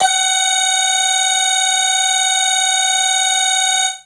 55bg-syn21-f#5.wav